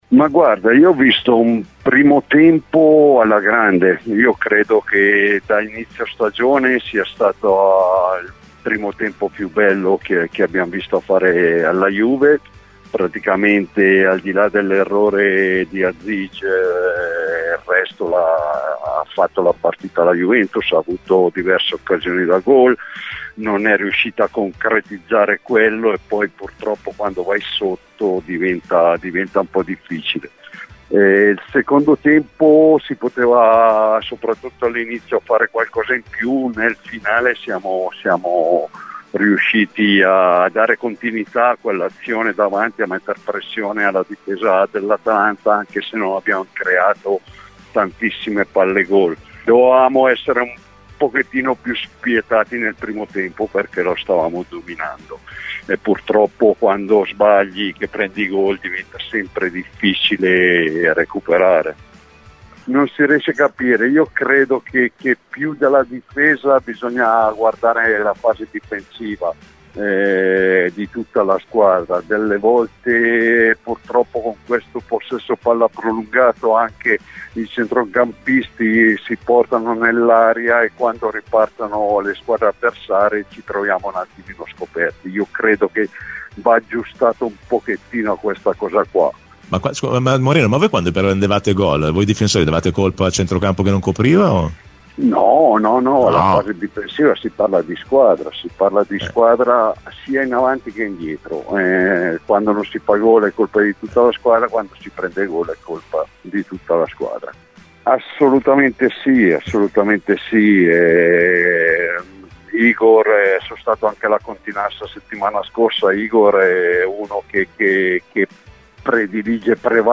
Torricelli a Radio Bianconera parla del momento Juve, contento della conferma di Tudor. L'ex bianconero indica la strada per tornare a vincere